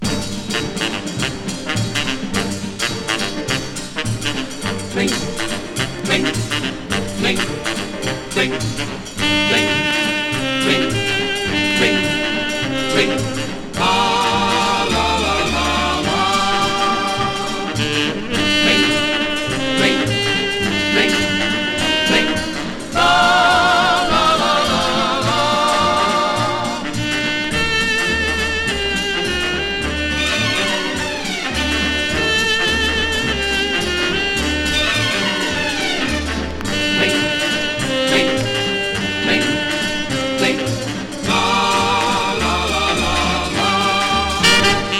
Jazz, Pop, Easy Listening　USA　12inchレコード　33rpm　Mono